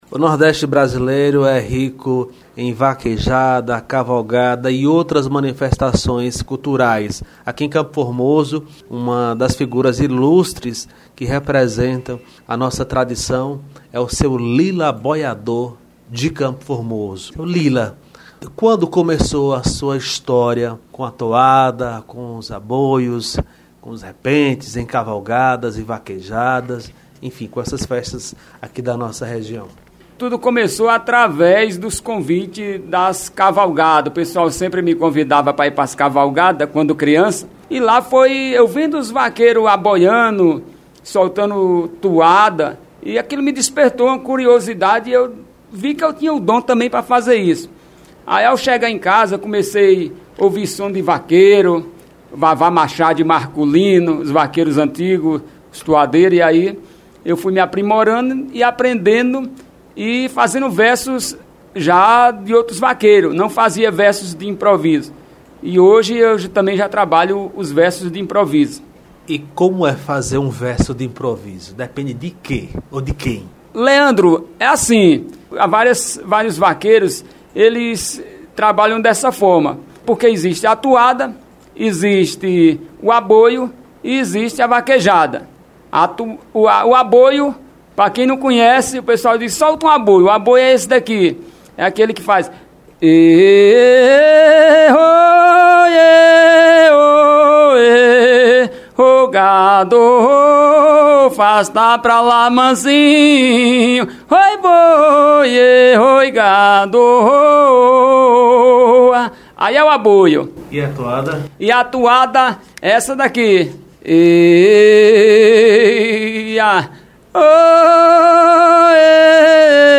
aboiador